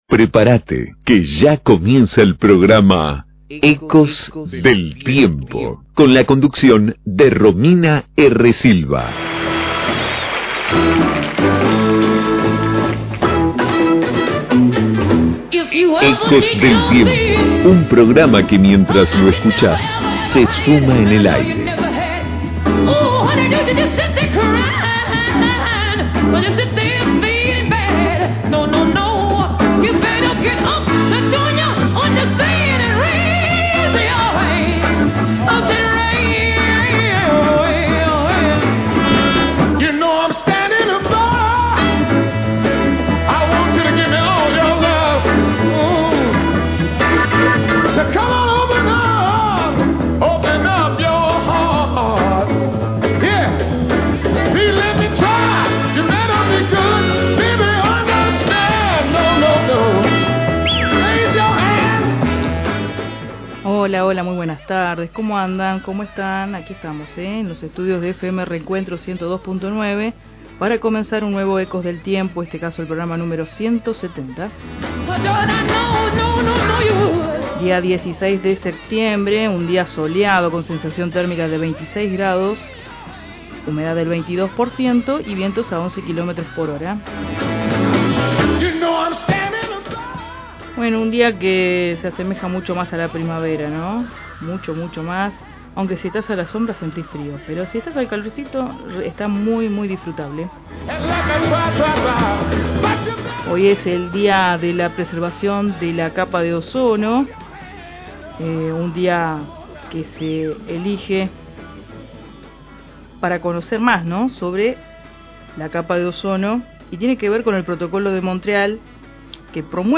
🎙🙂 Hablamos con la Prof. De Historia
♦Todos los Lunes de 15 a 17 horas por Fm Reencuentro 102.9